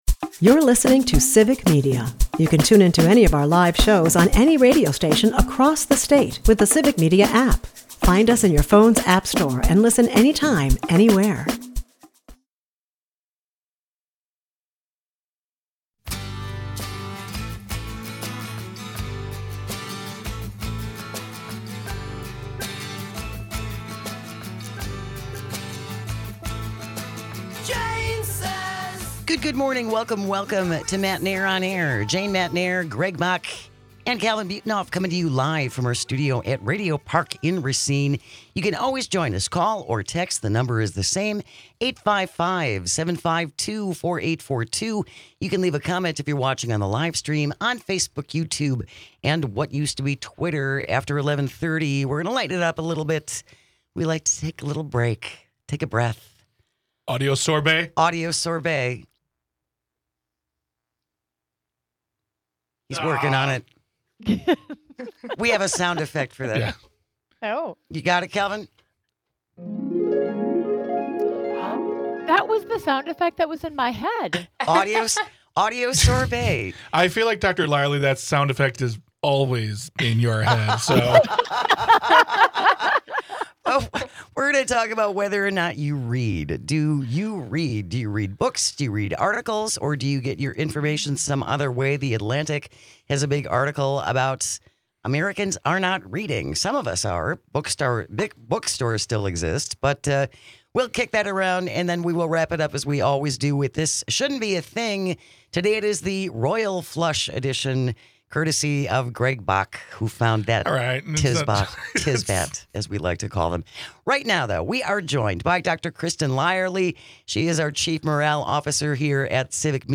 Matenaer On Air is a part of the Civic Media radio network and airs Monday through Friday from 10 am - noon across the state.